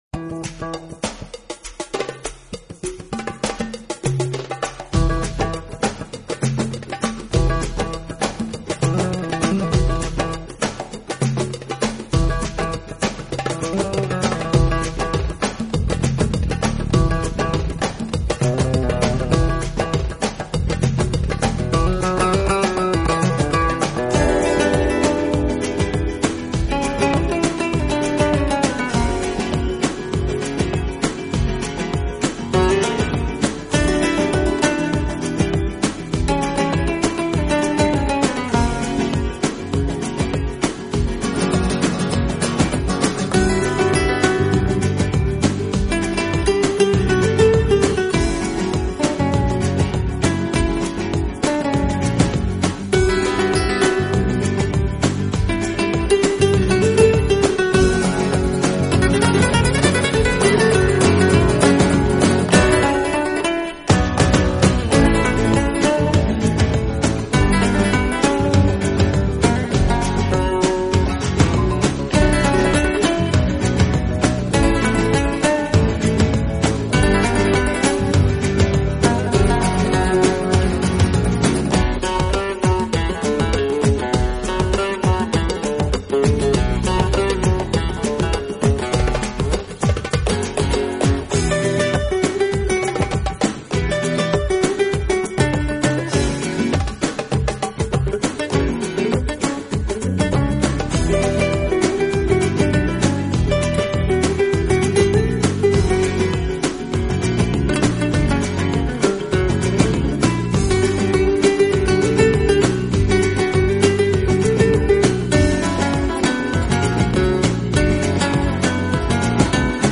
【吉他】
【弗拉门戈吉他】